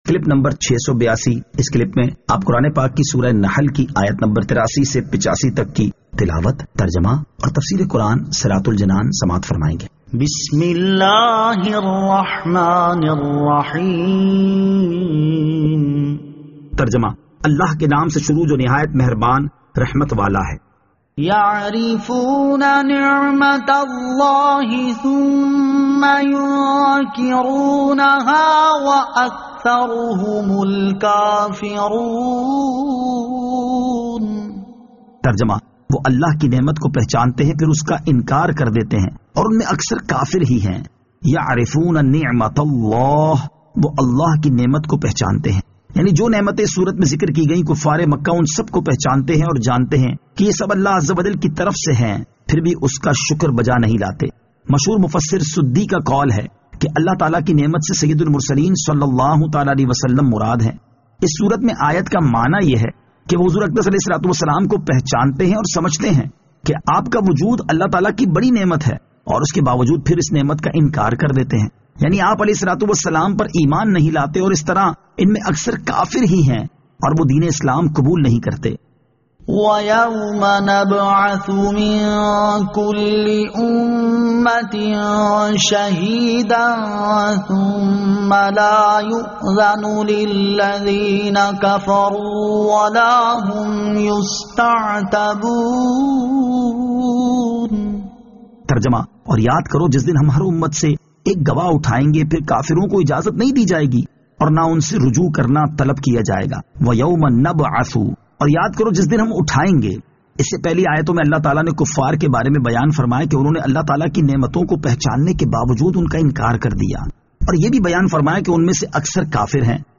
Surah An-Nahl Ayat 83 To 85 Tilawat , Tarjama , Tafseer